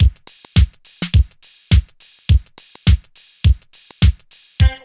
136 DRM LP-L.wav